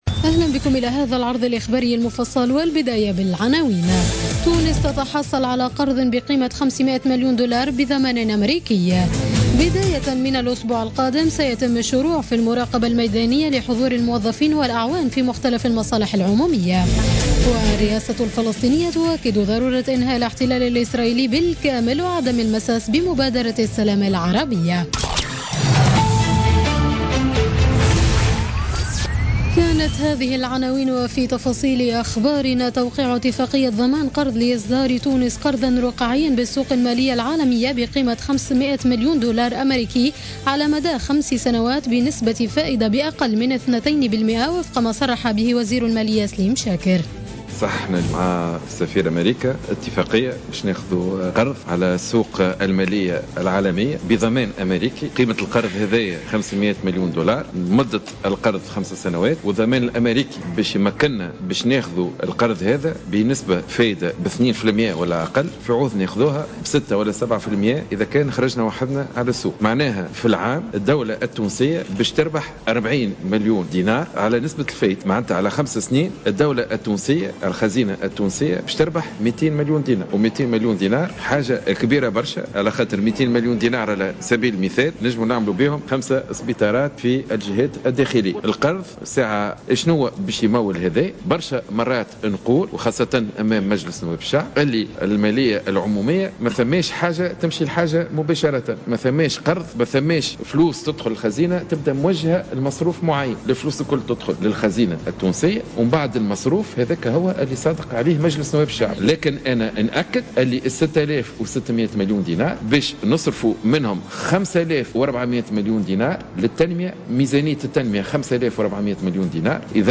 نشرة أخبارمنتصف الليل ليوم السبت 4 جوان 2016